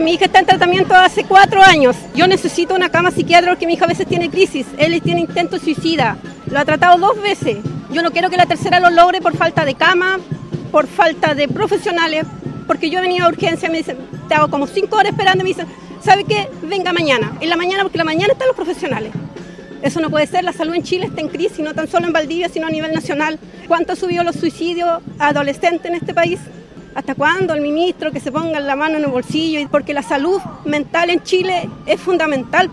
Testimonios de la protesta